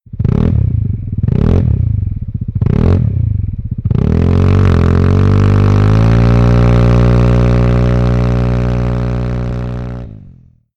Remus bez vložky:
Vespa_GTS_300_REMUS_BEZ_ZVUKOVE_VLOZKY.mp3